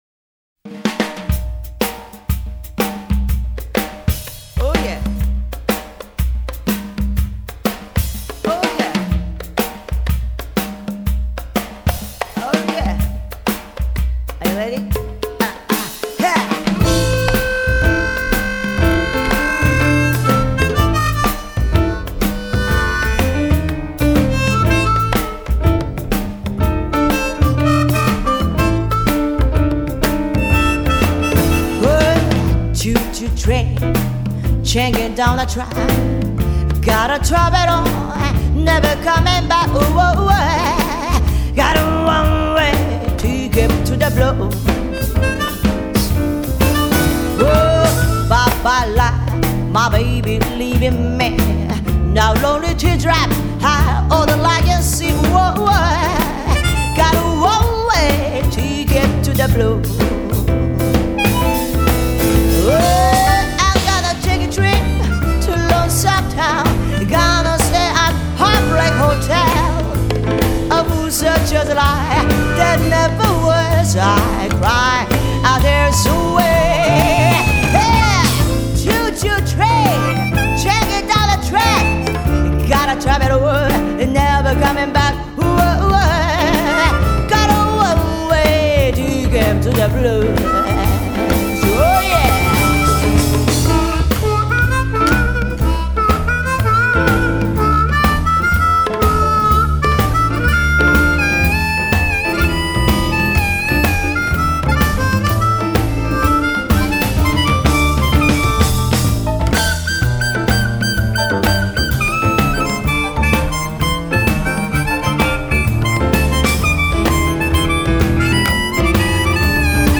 反倒是有点像美国的黑人女乐手
歌喉婉转间散发着野性和青春的朝气。